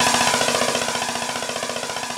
snarefill3.ogg